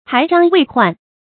诪張為幻 注音： ㄓㄡ ㄓㄤ ㄨㄟˊ ㄏㄨㄢˋ 讀音讀法： 意思解釋： 诪張：也作「X張」；欺騙作偽。